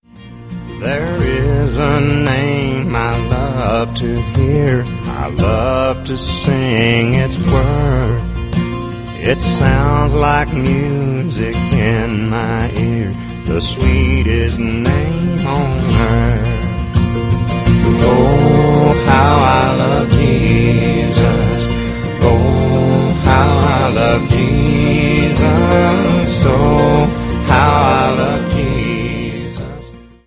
Songs od Faith, Worship and Praise
rein akkustisch
• Sachgebiet: Country